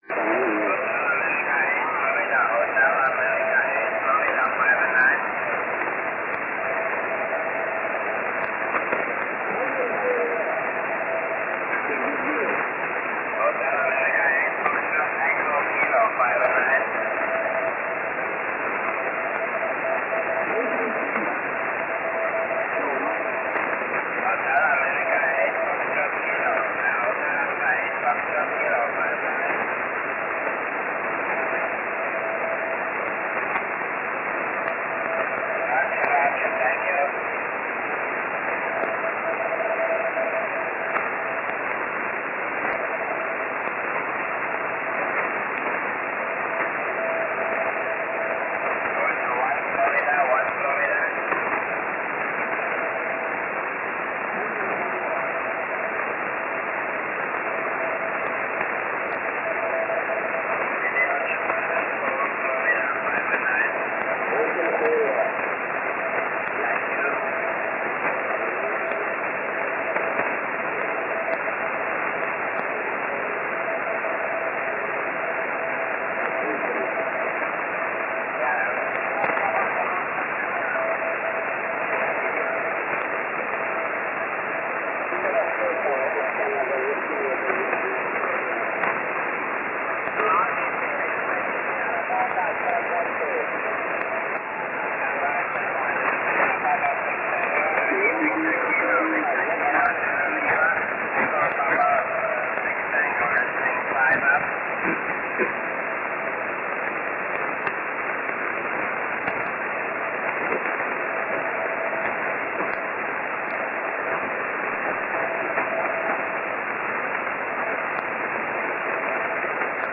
Jan/24 1932z EP6T 3.750MHz SSB